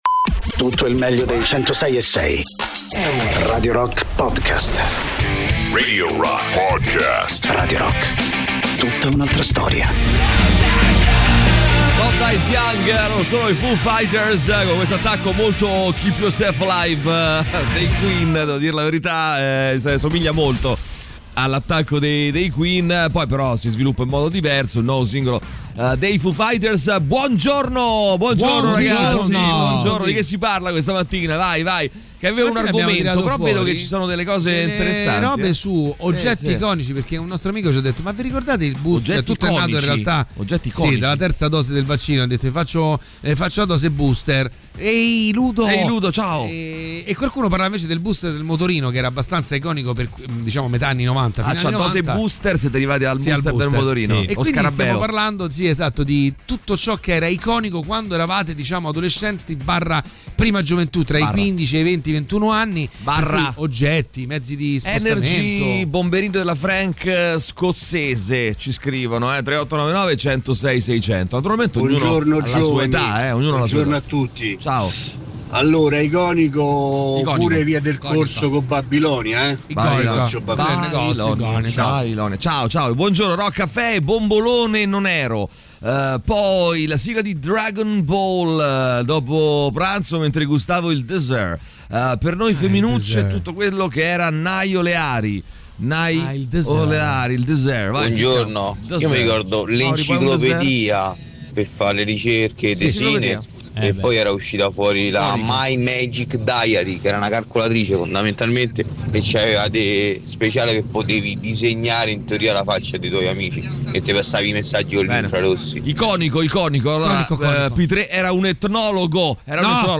in diretta dal lunedì al venerdì dalle 6 alle 10 sui 106.6 di Radio Rock